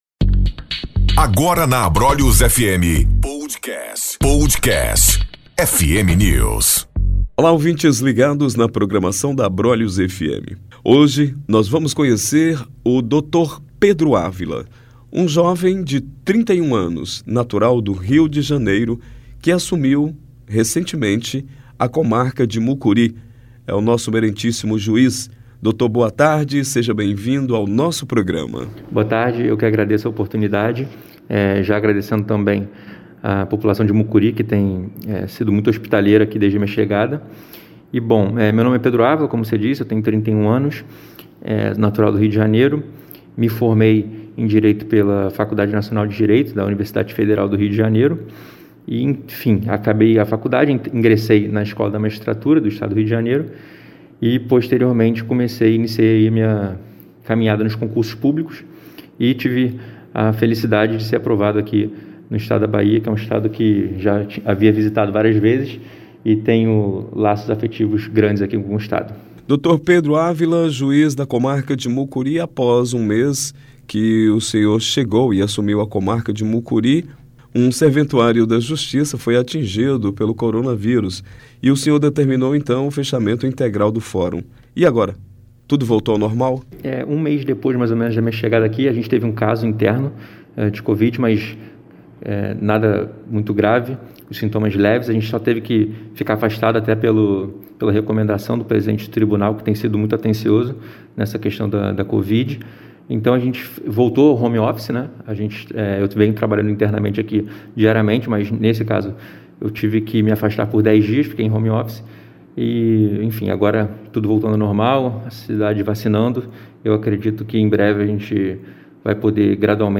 Entrevistamos o excelentíssimo Juiz da Comarca de Mucuri, Dr. Pedro Cardillofilho de Proença Rosa Ávila, ele que é o titular da Vara Criminal, além de juiz-substituto da Vara Cível e da Justiça Eleitoral, natural do Rio Janeiro, 31...